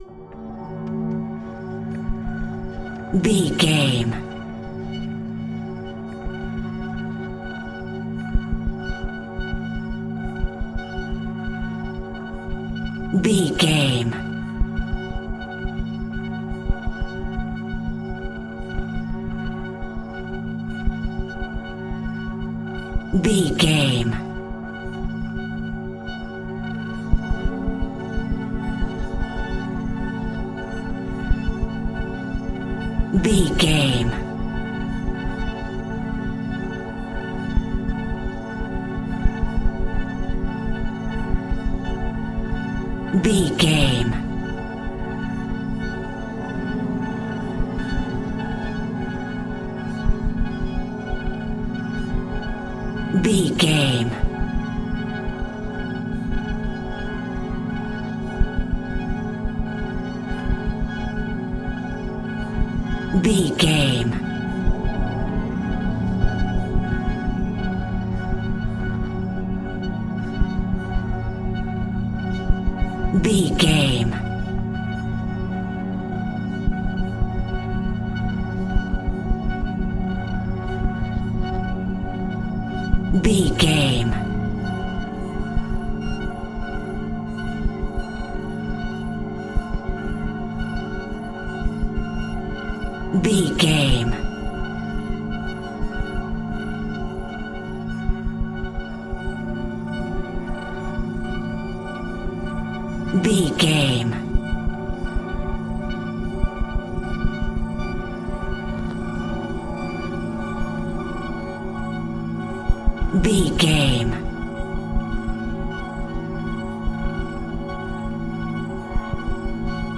Atonal
ominous
suspense
eerie
synthesizer
Horror Ambience
Synth Pads
Synth Ambience